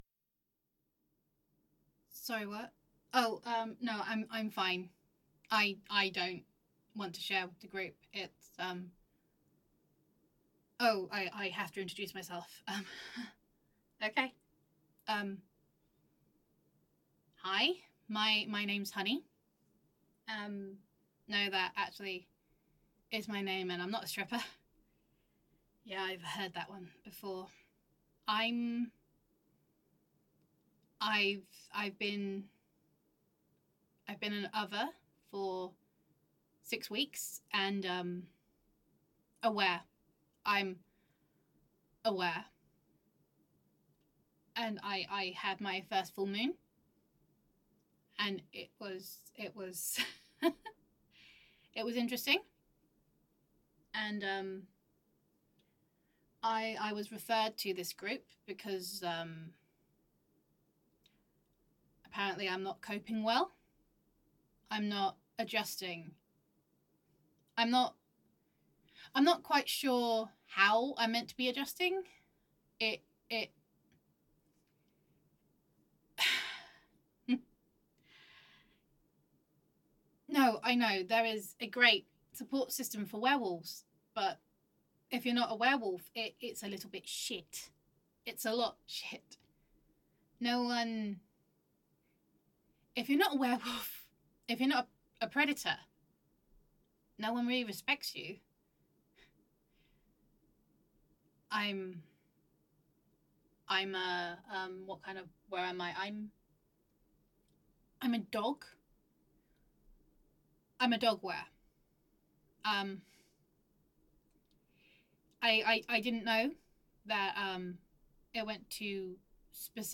Terrible audio quality, but it could be a solid idea for a series.
[F4A] The Support Group [Were][Other][the Grand Reveal Shared Universe][Supernatural Roleplay]